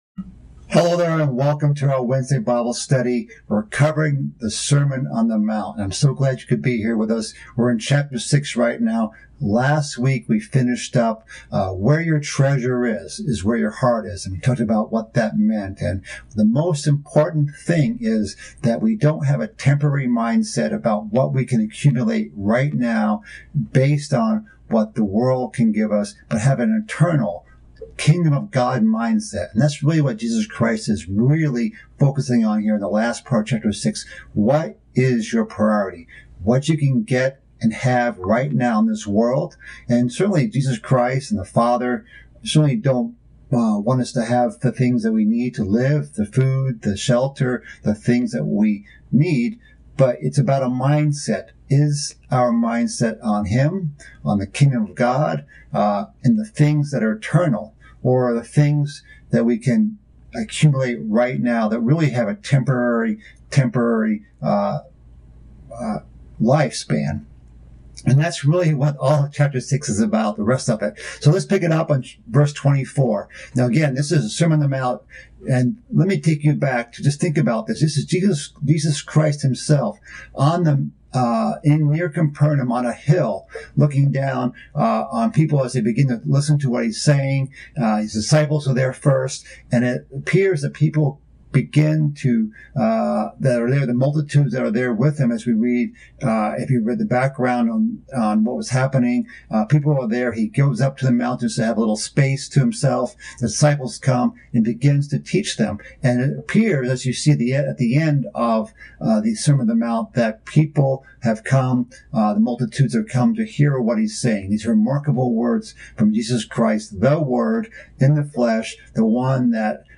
This is part of a mid-week Bible study series about the sermon on the mount. This part concludes chapter 6 and covers the topics of not worrying, and seeking God's kingdom and His righteousness.